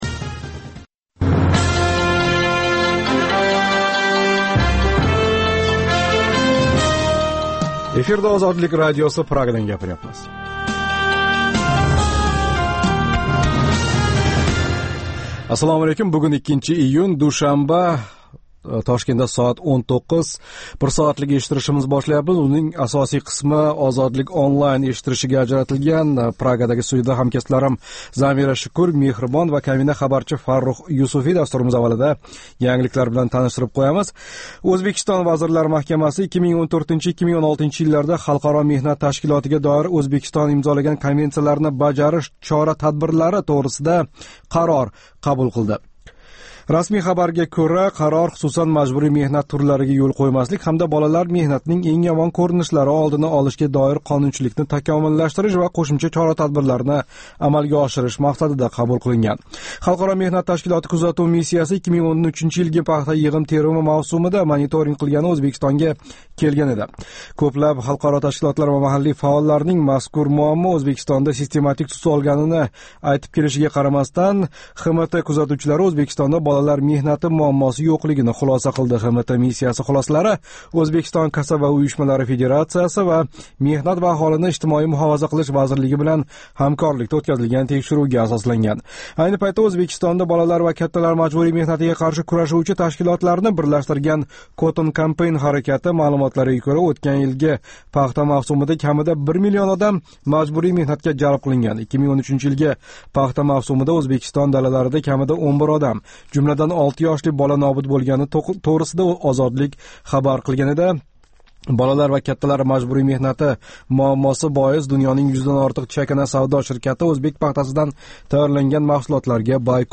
Жонли эфирдаги кечки дастуримизда сўнгги хабарлар, Ўзбекистон, Марказий Осиë ва халқаро майдонда кечаëтган долзарб жараëнларга доир тафсилот ва таҳлиллар билан таниша оласиз.